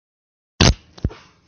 真实的屁 " 屁2
描述：真屁
Tag: 现实 放屁 真正